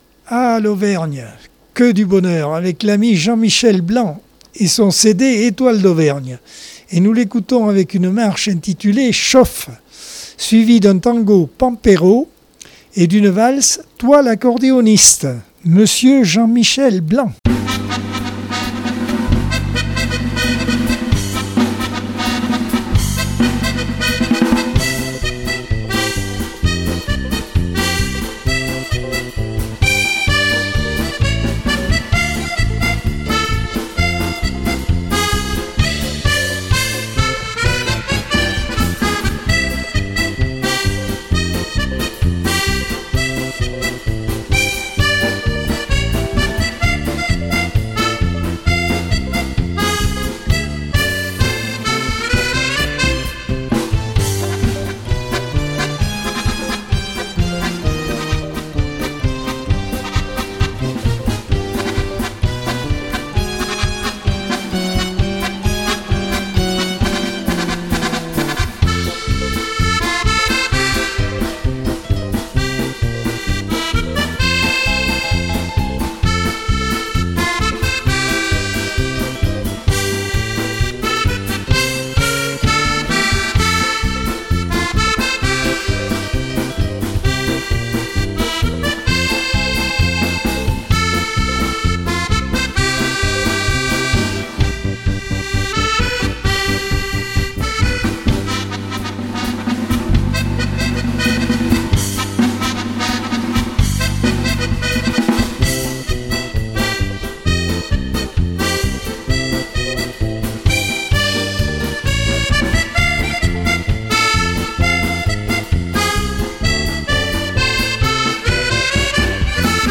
Accordeon 2022 sem 46 bloc 4.